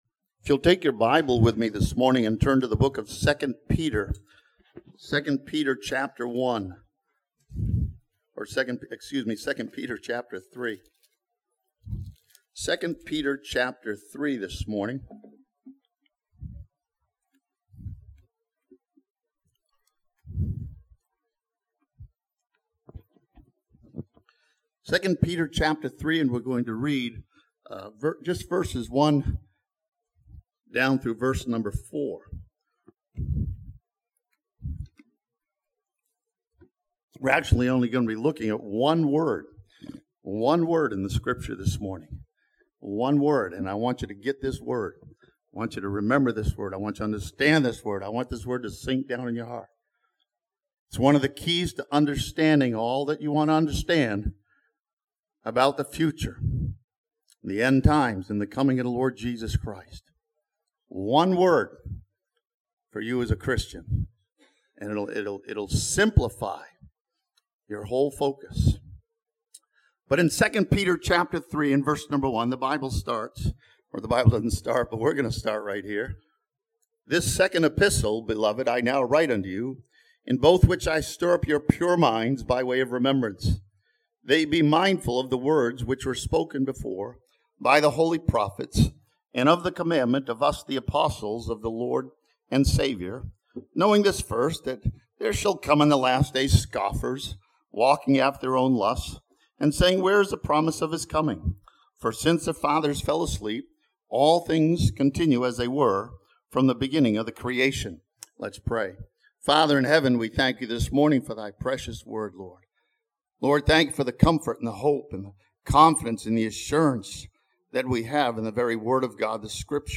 This sermon from 2 Peter chapter 3 sees Jesus Christ as the beloved Son of God who has accepted us into the Beloved.